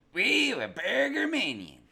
Play, download and share burger follow original sound button!!!!
follow-alert_nfgPYGk.mp3